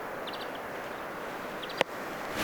tuollaiset viherpeipon äänet
tuollaiset_viherpeipon_aanet.mp3